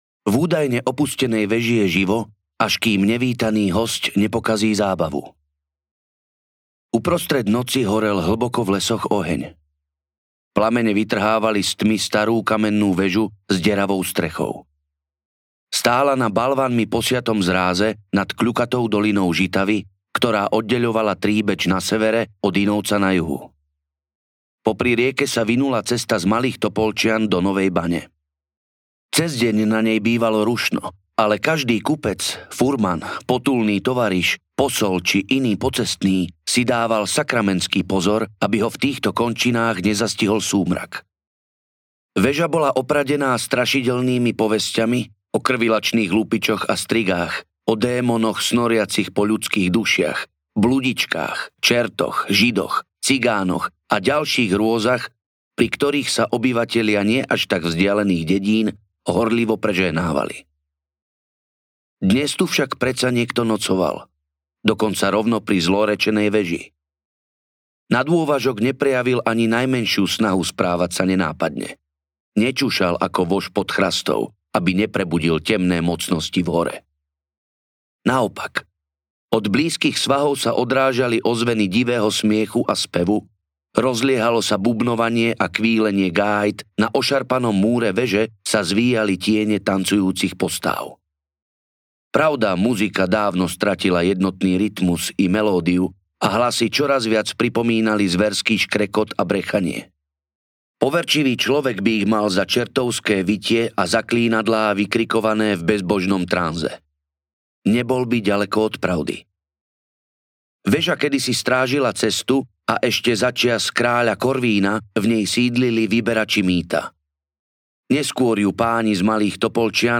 Pre hrsť dukátov audiokniha
Ukázka z knihy